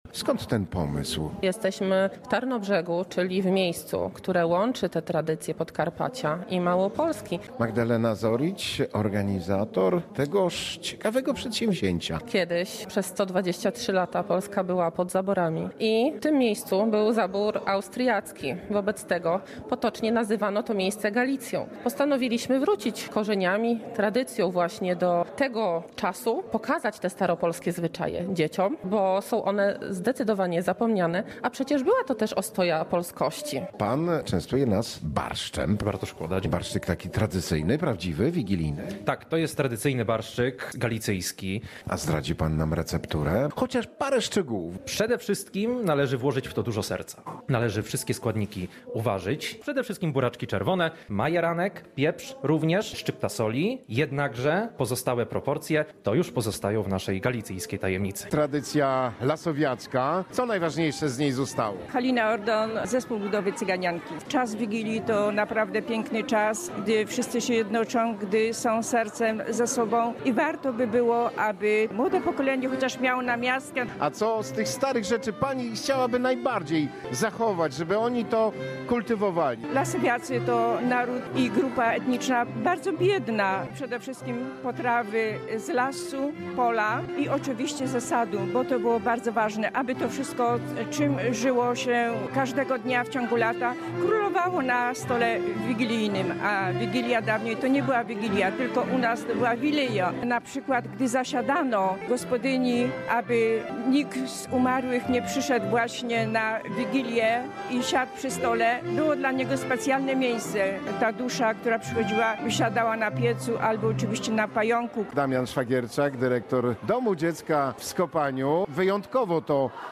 Na zakończenie wspólnie śpiewano kolędy, były życzenia i łamanie się opłatkiem.